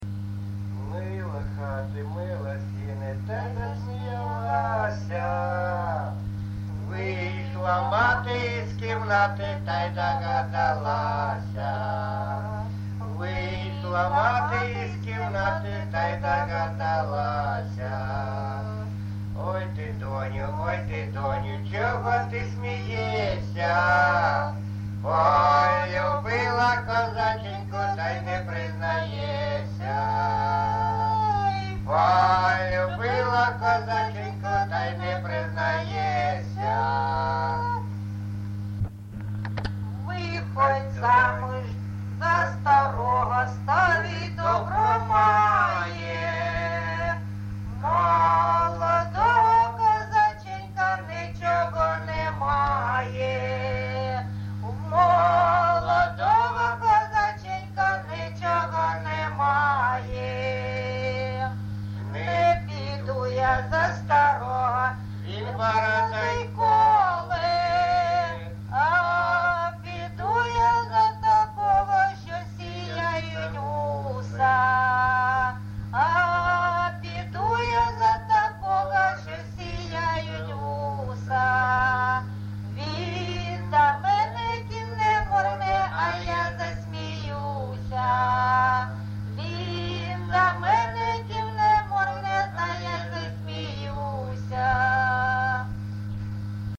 ЖанрПісні з особистого та родинного життя
Місце записус. Бузова Пасківка, Полтавський район, Полтавська обл., Україна, Полтавщина